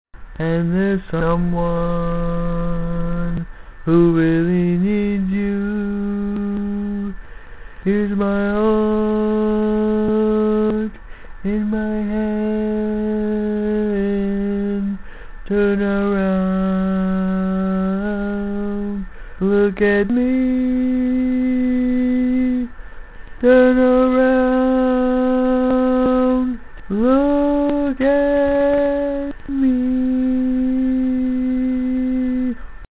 Key written in: C Major
Each recording below is single part only.